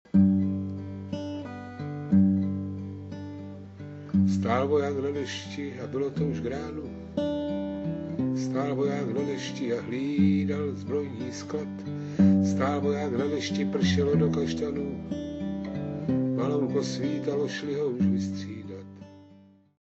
vyprávěcí recitativní tón.